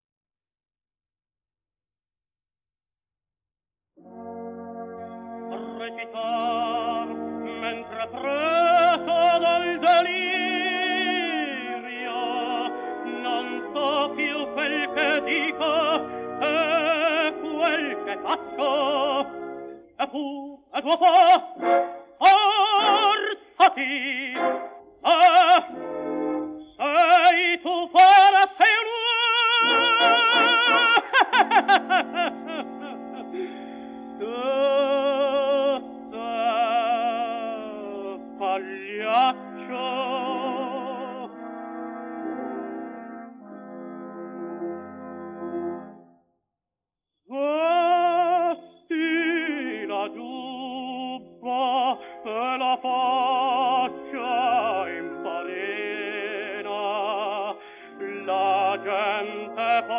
denoised_cropped.wav